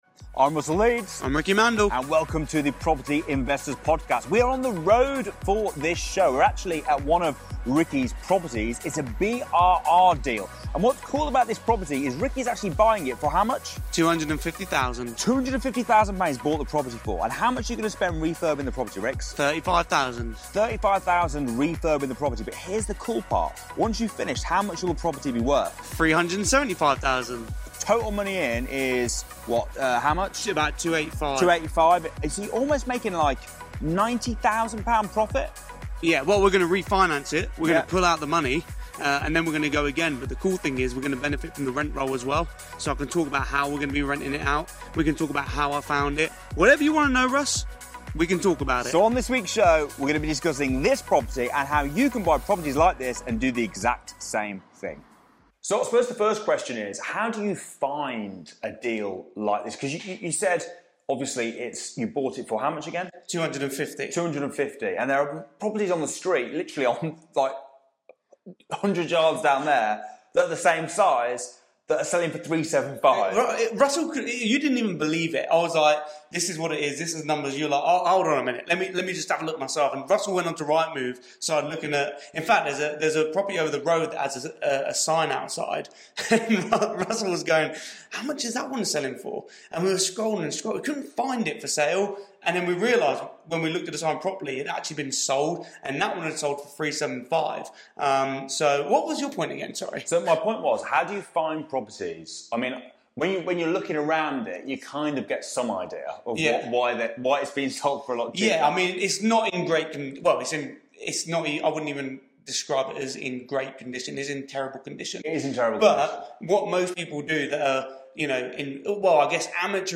the light-hearted and educational show